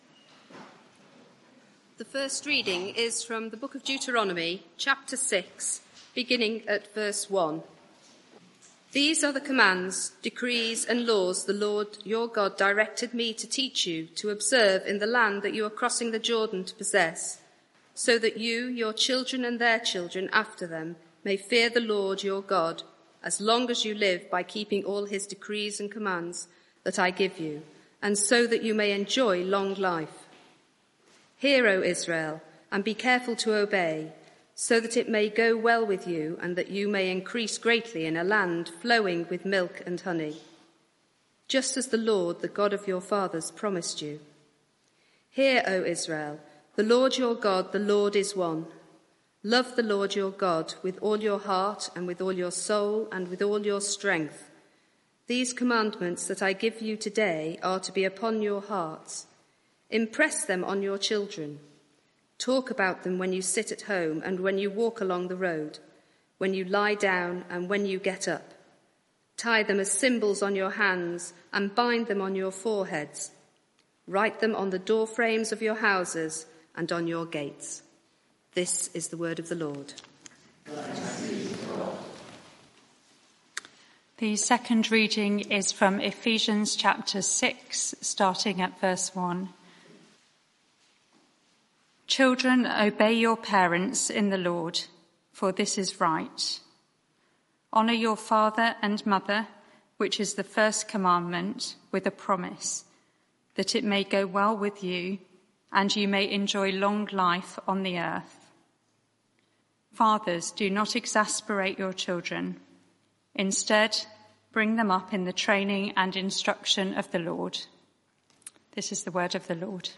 Media for 6:30pm Service on Sun 24th Mar 2024
Theme: Happy Families Sermon (audio)